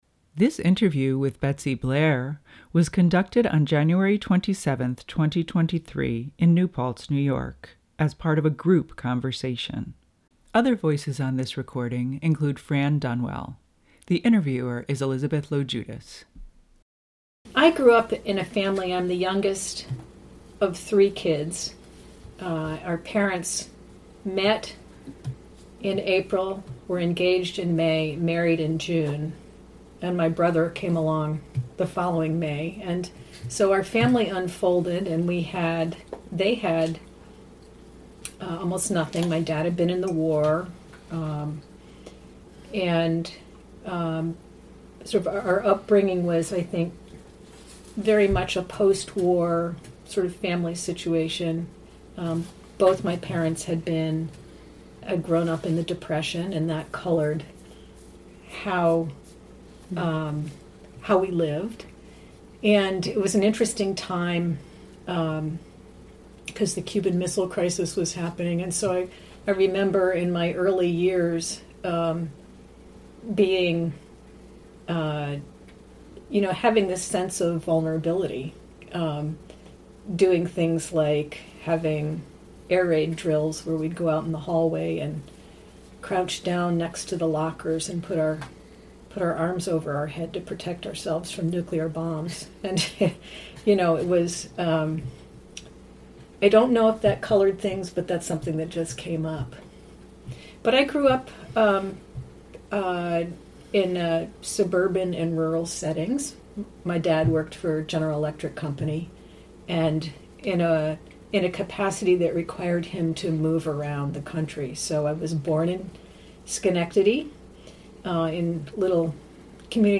She discusses a variety of experiences, including living with her family in Italy, working on a Japanese fishing vessel in the Pacific Northwest, and her early career. This recollection took place as part of a group conversation.